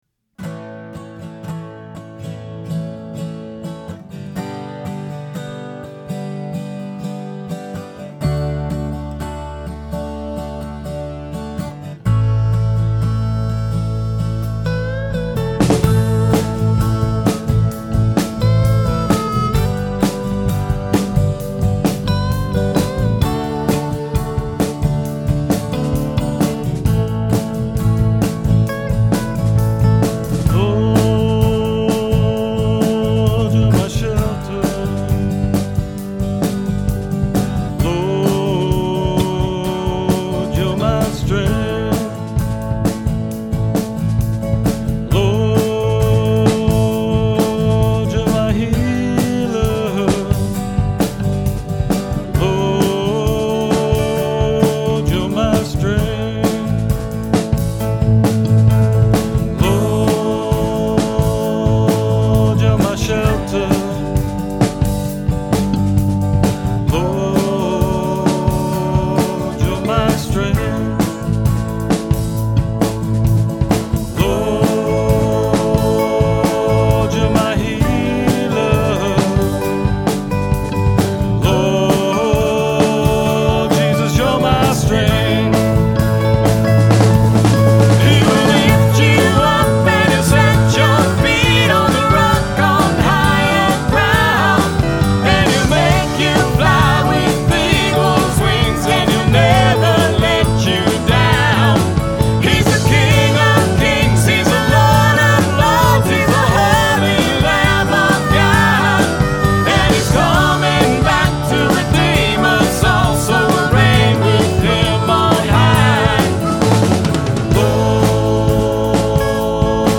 The song below is from our worship band recording (Kerr Avenue).